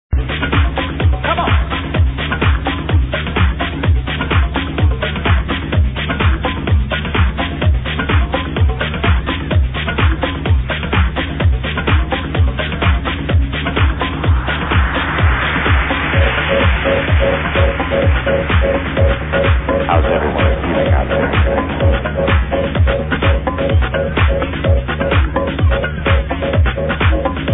Cool track with a voice at the end of this clip ...sample attached